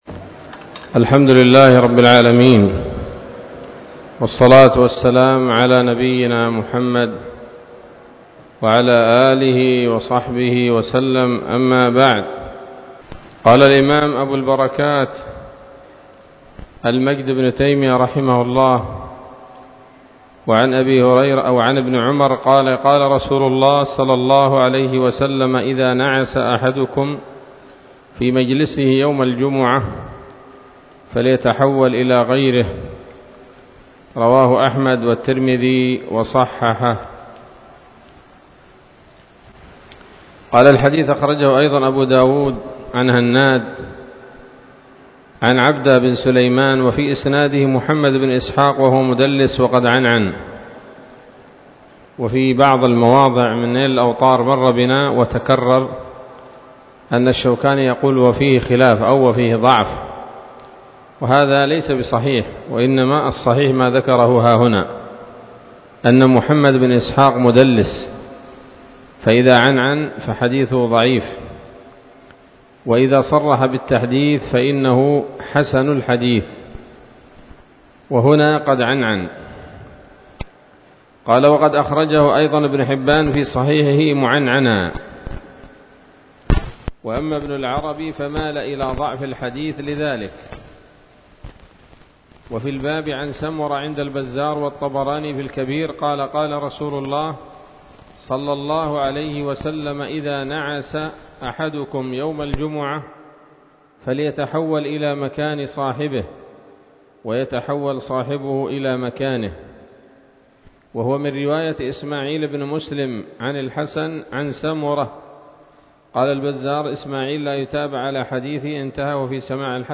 الدرس السابع عشر من ‌‌‌‌أَبْوَاب الجمعة من نيل الأوطار